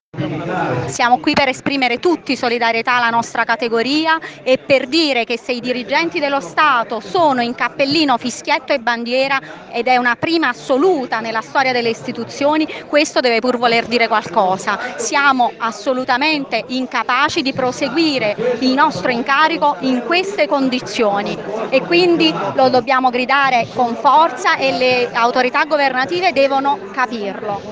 A Roma, in Piazza S. Cosimato, a due passi dal Ministero dell’istruzione, è gremita (si parla di circa 3mila persone), e migliaia di altri DS e cittadini sono collegati in streaming.
Uno slogan continuo accompagnano gli interventi: “Basta, basta, perché non si ha più pazienza”.